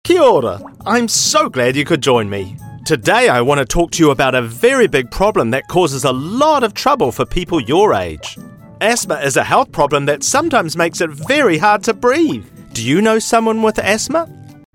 Voice Samples: Medical
EN NZ
male